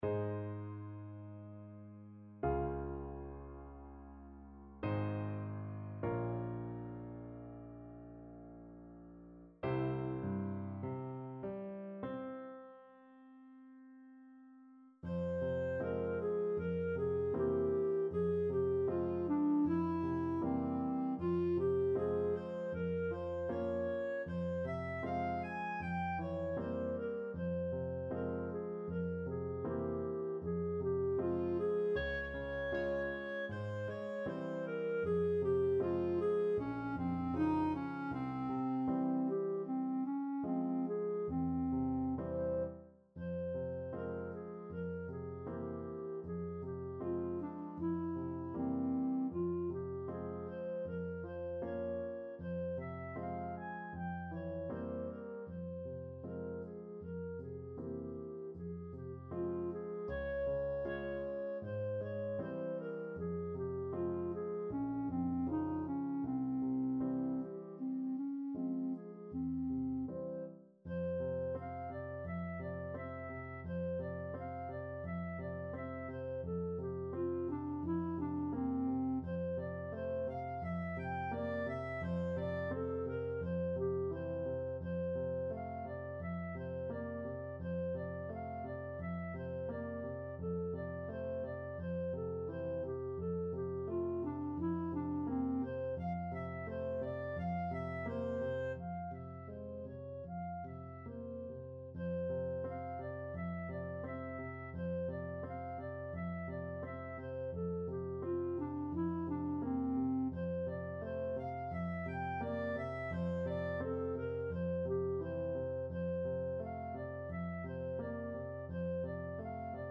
Clarinet
Ab major (Sounding Pitch) Bb major (Clarinet in Bb) (View more Ab major Music for Clarinet )
Largo
4/4 (View more 4/4 Music)
mussorgsky_une_larme_CL.mp3